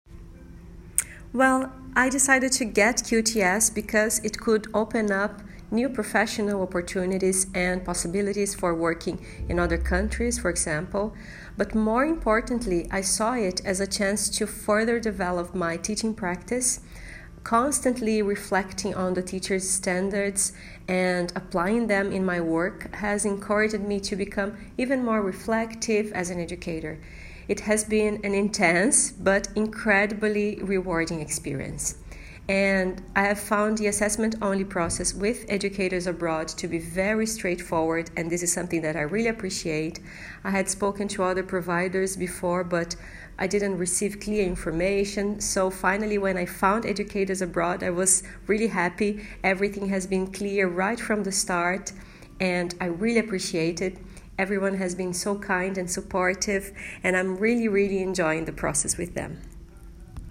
One of our proud graduates, describes her experience during the AOQTS Support Programme Process.